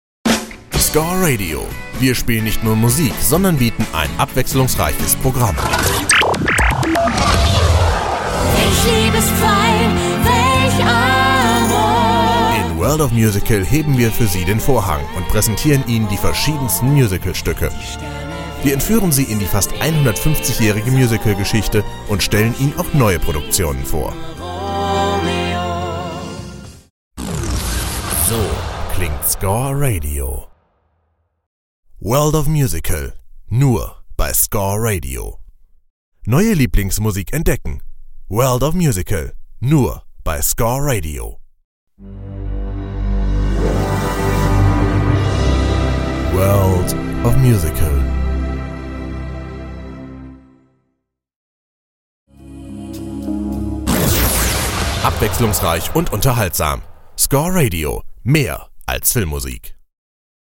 Junge, kraftvolle und warme Stimme für Off, Voice-Over und mehr.
Kein Dialekt
Young, impressive and warm Voice for Off, Voice-Over and more